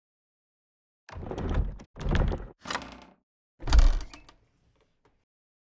sfx_chest_open.ogg